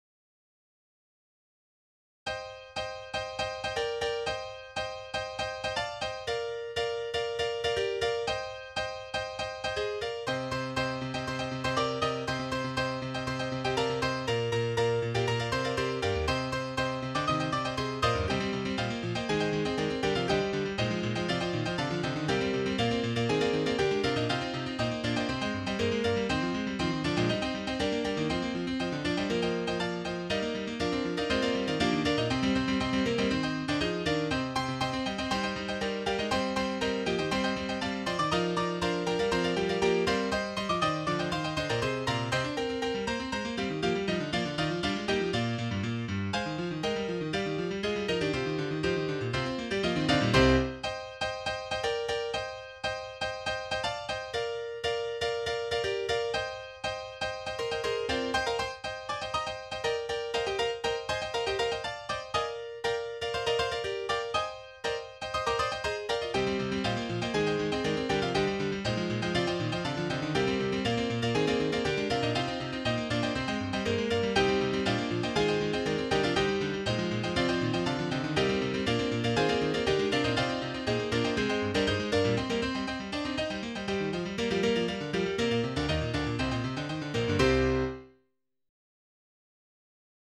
Title Confusion Opus # 13 Year 0000 Duration 00:01:30 Self-Rating 3 Description Something I wrote while in high school on an Apple II GS. mp3 download wav download Files: wav mp3 Tags: Solo, Piano Plays: 1505 Likes: 0